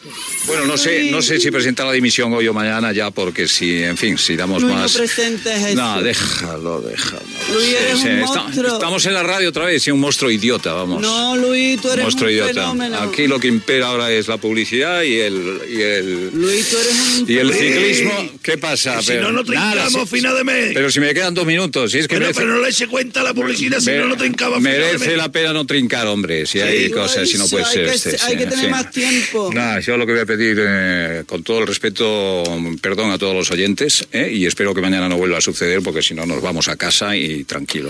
Luis del Olmo talla la connexió amb José María García.
Info-entreteniment